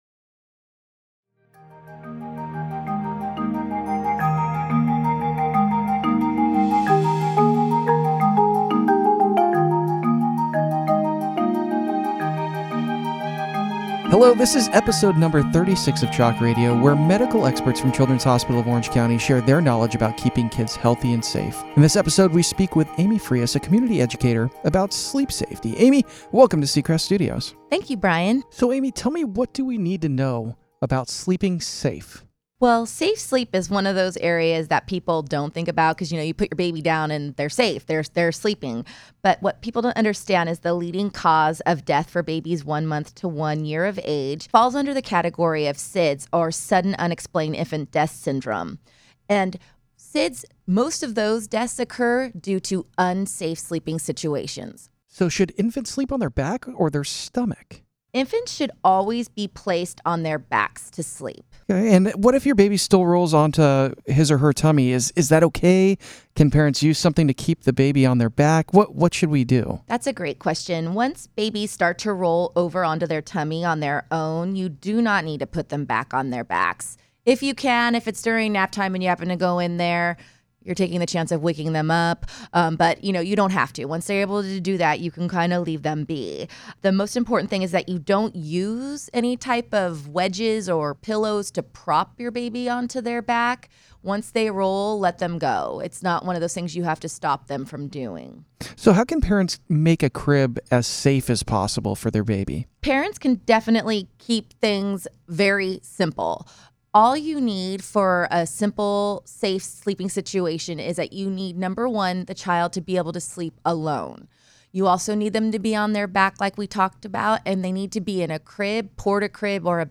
October is Sudden Infant Death Syndrome (SIDS) Awareness Month, but parents should remember two things year-round to keep babies safe during sleep: babies should sleep alone and on their backs, a CHOC community educator tells CHOC Radio.